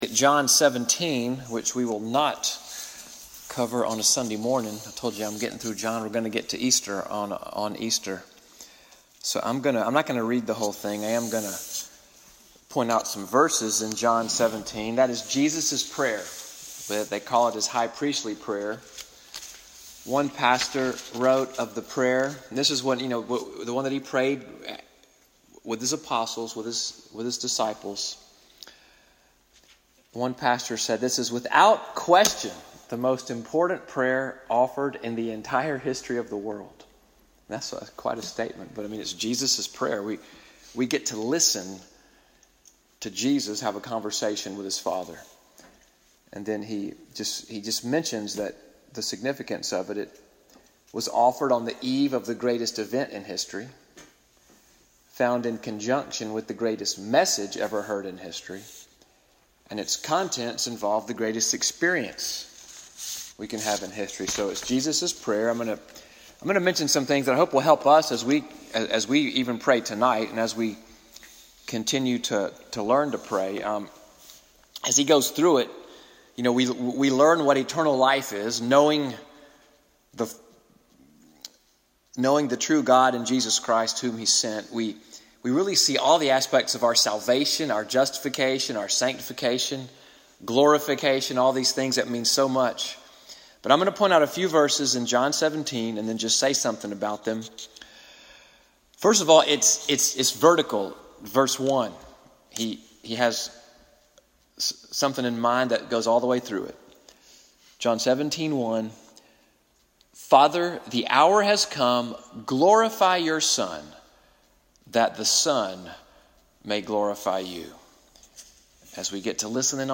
Wednesday Night Bible Study before Prayer at NCPC-Selma.